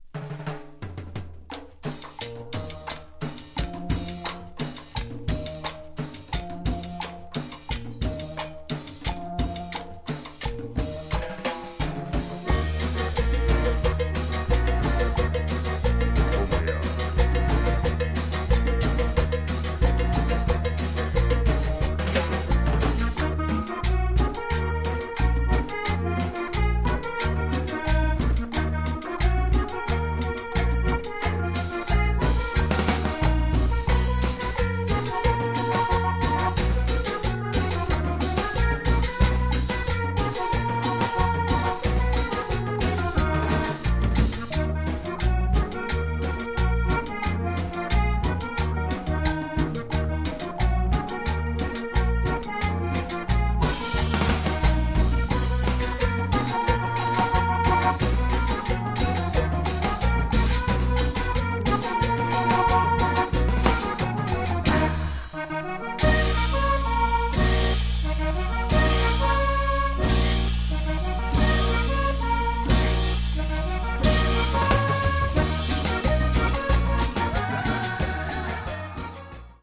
Tags: Colombia , Bogotá , Psicodelico
Bonkers cumbias con acordeón with this double sider release.